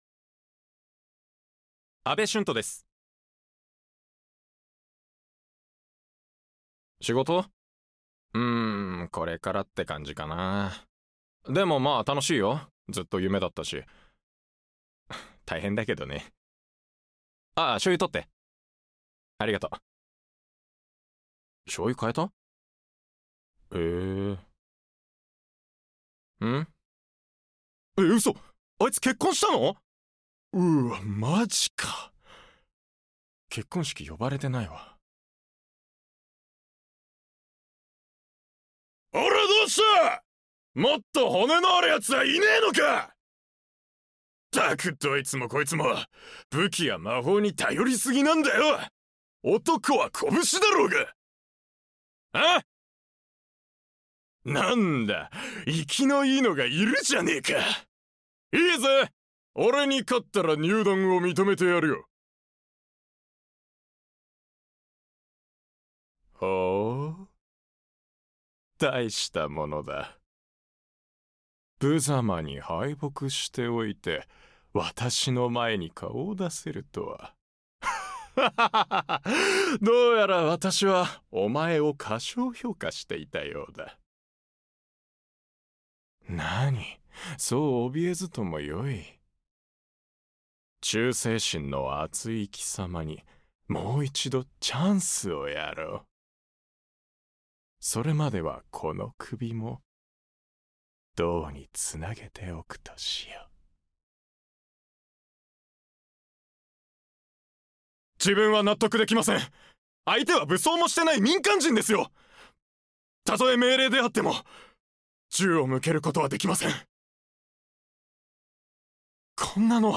サンプルボイス
方言 伊達弁